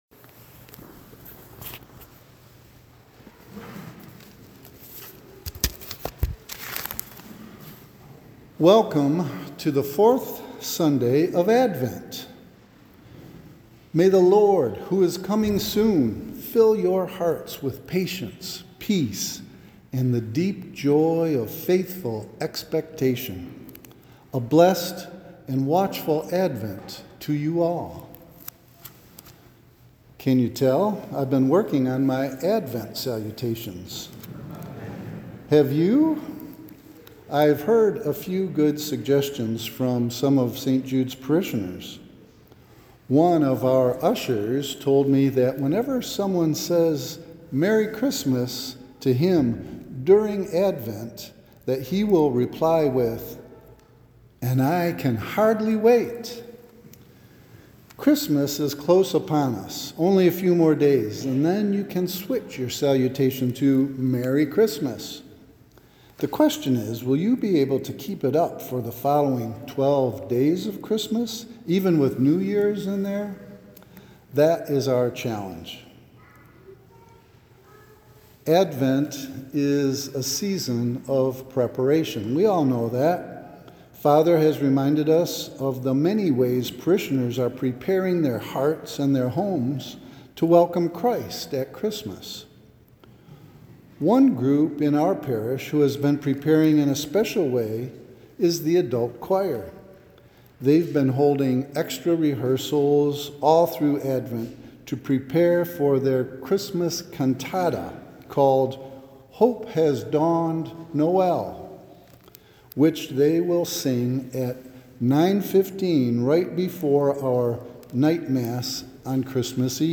Homilist